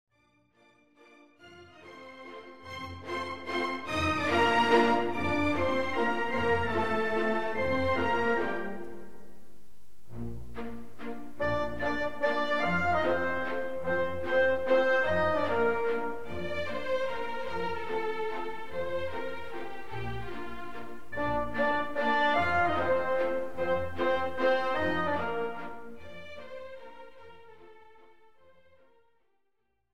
Classical and Opera
OPERA